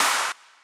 clap01.ogg